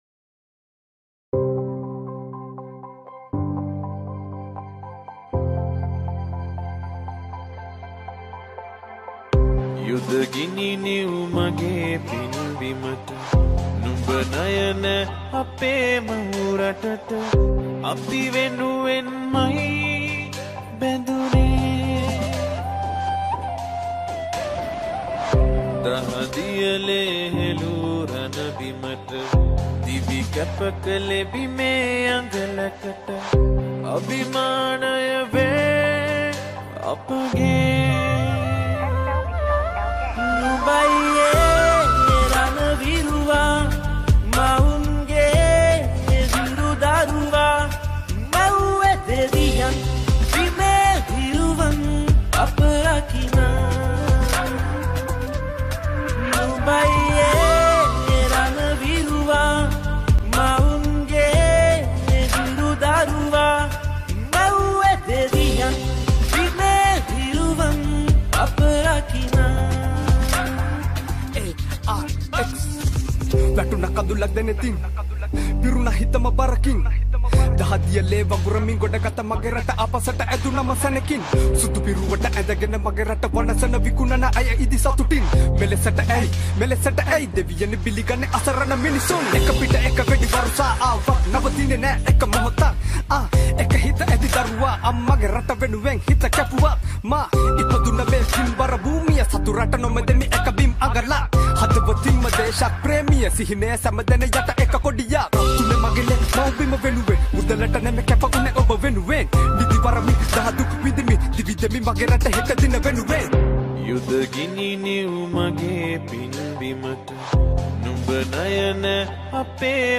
Vocals
Rap
Flute